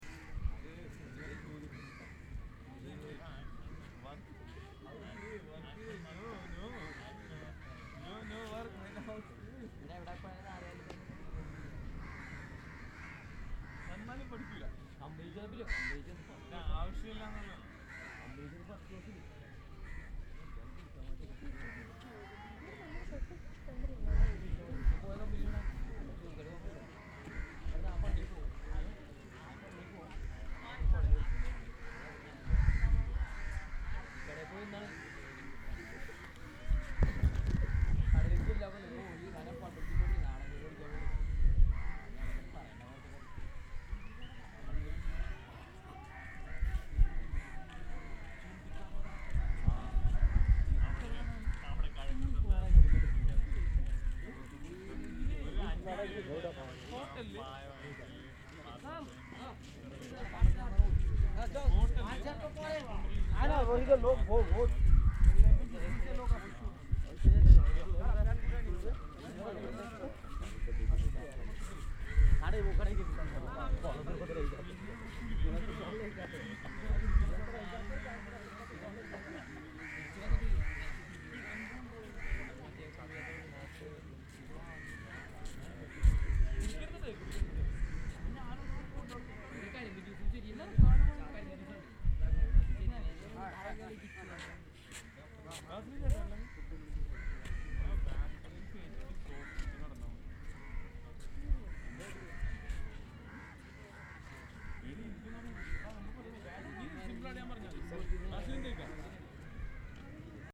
09/02/2014 09:00 Je me réveille à Cochin.
C'est ce type tout seul sur un banc au bout d'une jetée, qui file à bouffer aux corbeaux devant le port industriel.